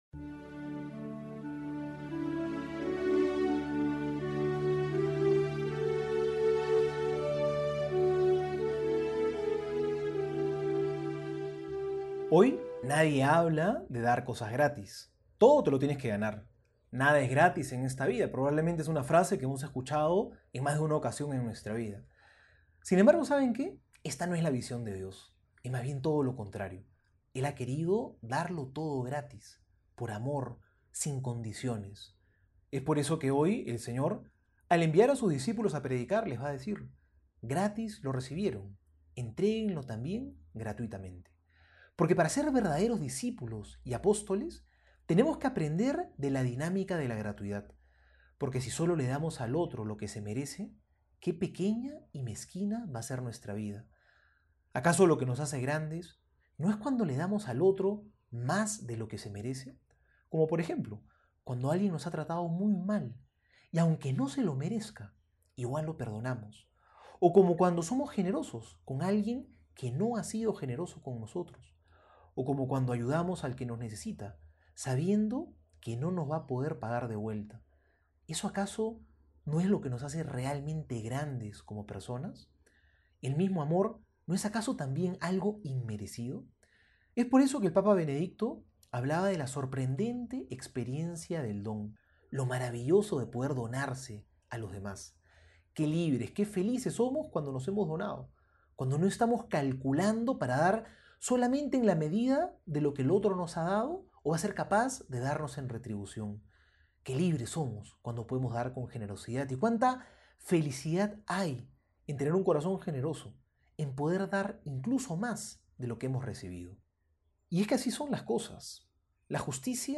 Homilía para hoy:
Jueves Homilia Mateo 10 7-15.mp3